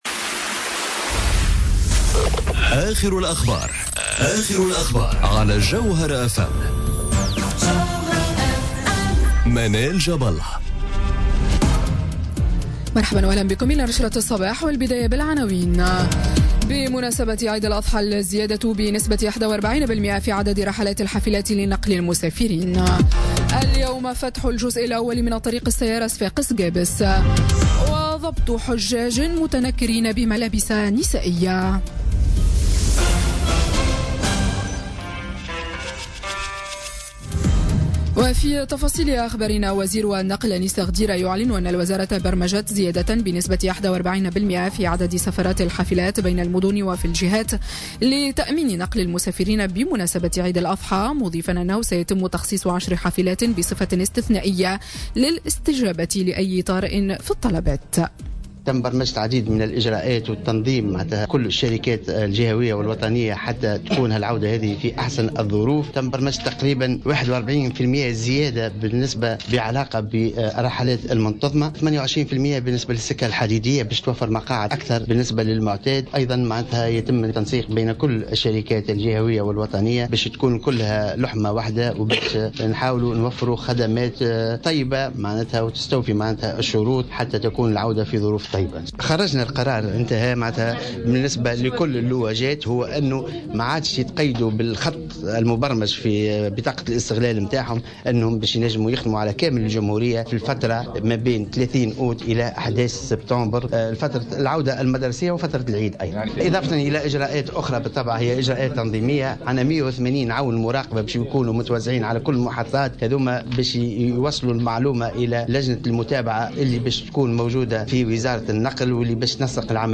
Journal Info 07h00 du mardi 29 Août 2017